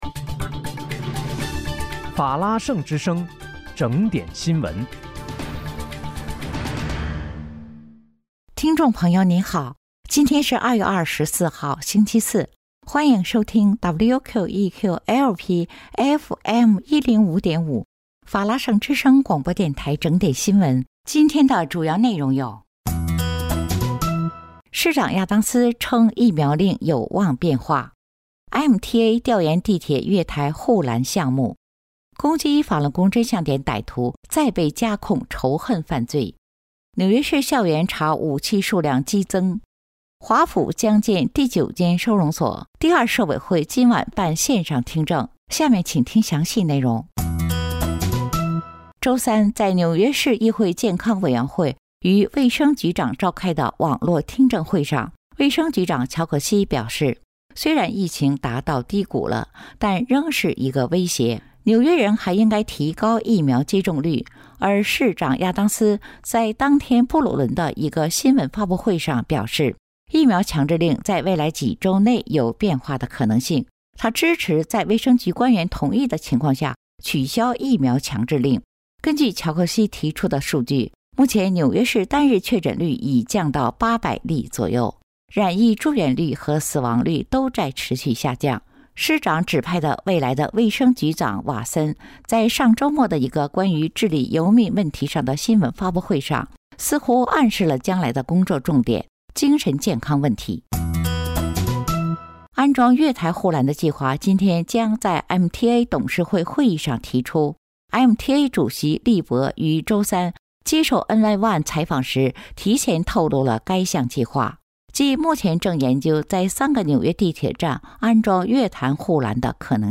2月24日（星期四）纽约整点新闻